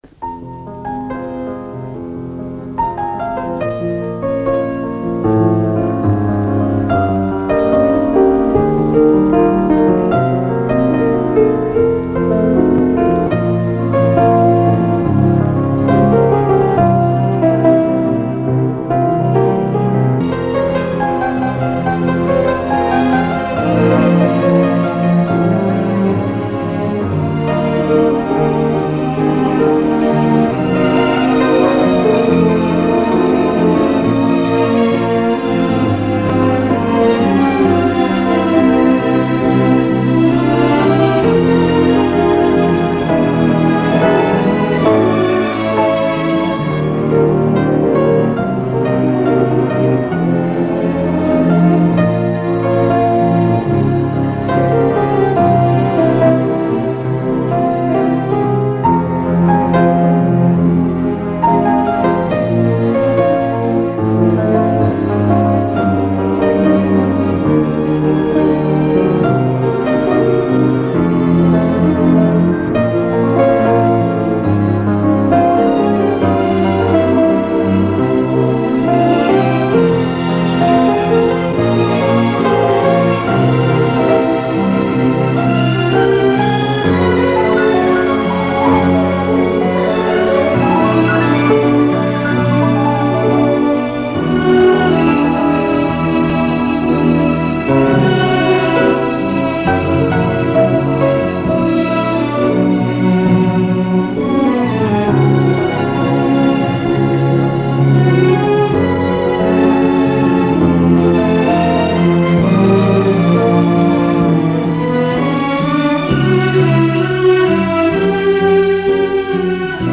Спасибо,красивая мелодия.